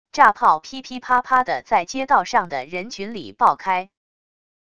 炸炮噼噼啪啪的在街道上的人群里爆开wav音频